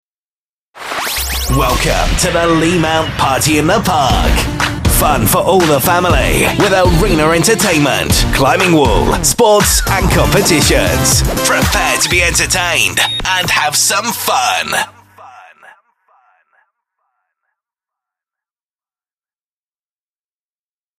Example 4 – Custom audio for Shroggs Party in the Park